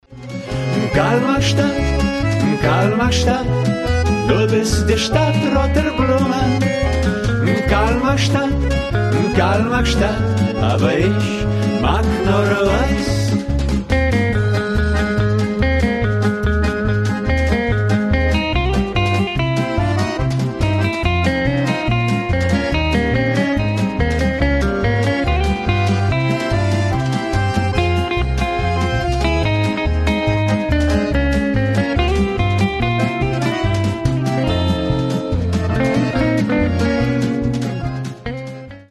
• Качество: 320, Stereo
гитара
90-е